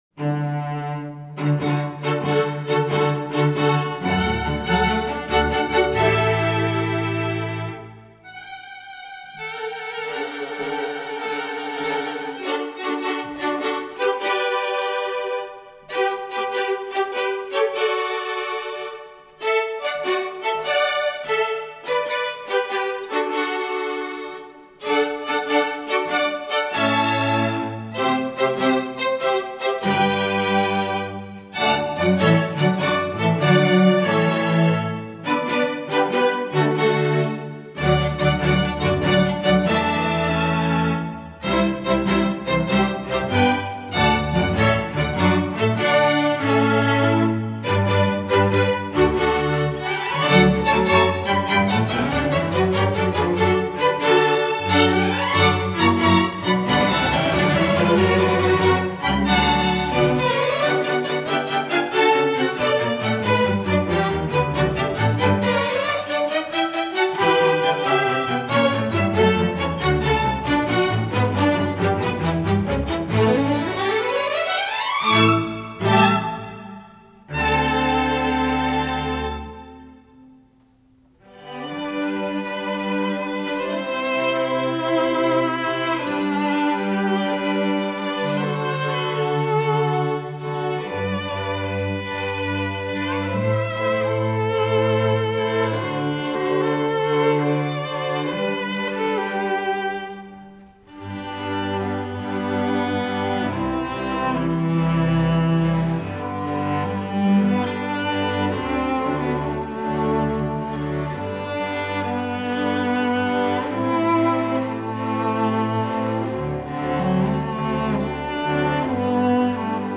Instrumental Orchestra String Orchestra
the music covers a wide gamut of styles
String Orchestra